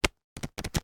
Звуки паспорта
Шуршание страниц паспорта от скуки